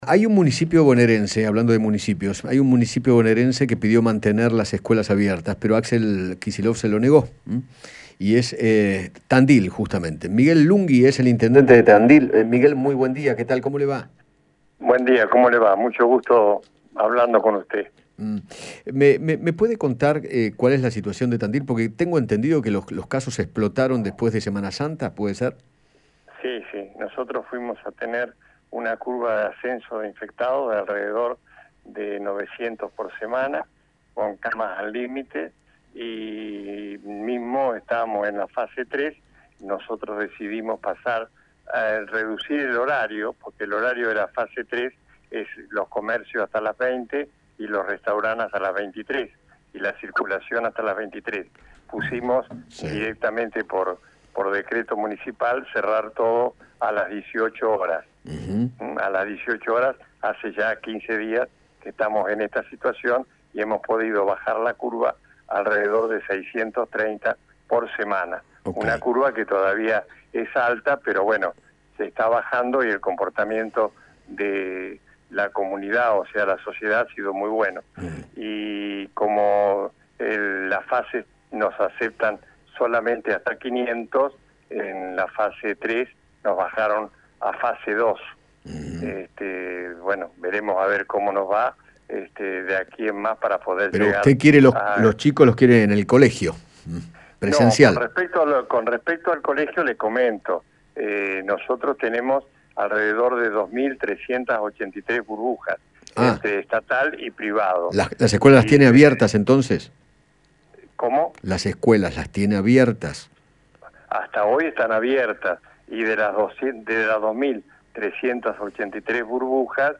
Miguel Lunghi, intendente de Tandil, conversó  con Eduardo Feinmann acerca de la evolución de la curva de contagios en aquella localidad e hizo referencia a la suspensión de las clases por 15 días.